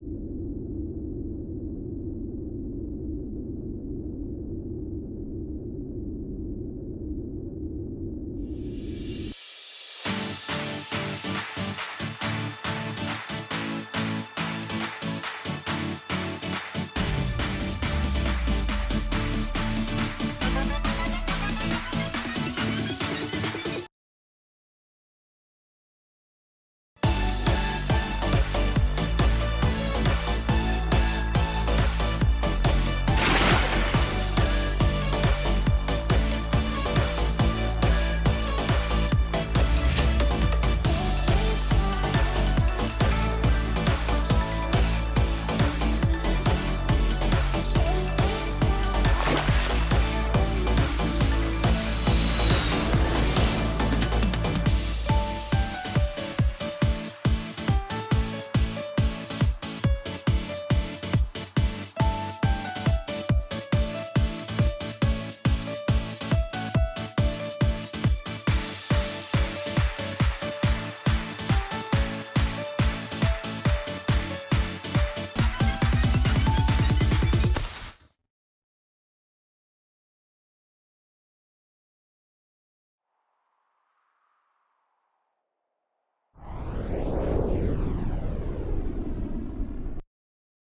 二人声劇「プロペラ飛行」